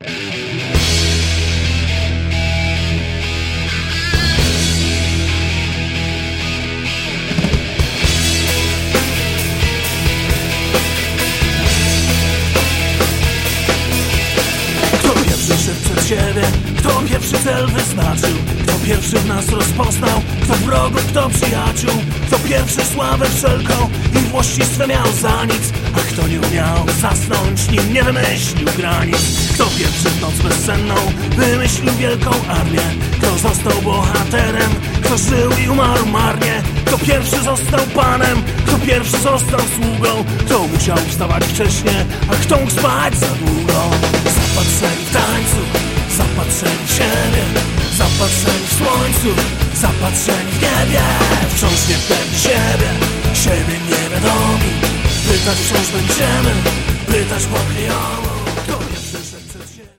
A gramy rocka z elementami punk i ska.
ten schowany, subtelny chórek-murmurando